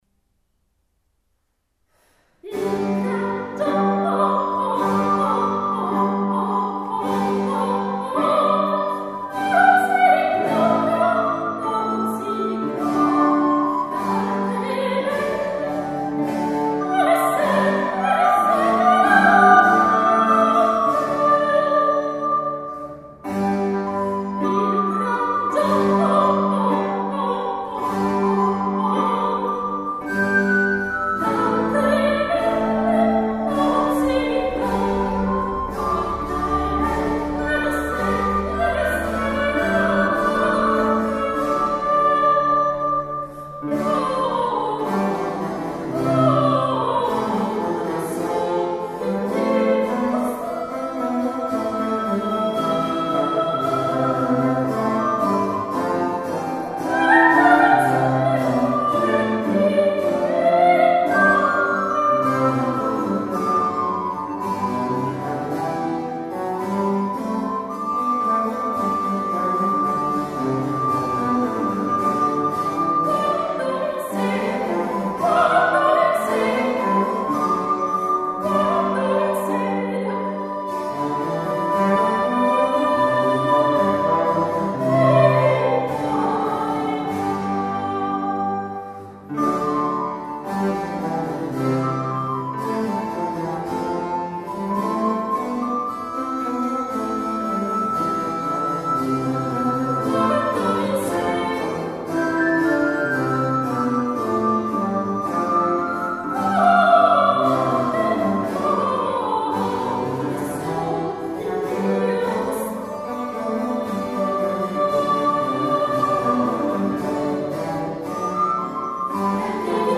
la registrazione è stata effettuata nel Maggio del 2011 nell'Oratorio dell’Annunziata di Piana Crixia (Savona).
Sono state utilizzate esclusivamente copie di strumenti rinascimentali.
L’eco è solo quella naturale dell'oratorio,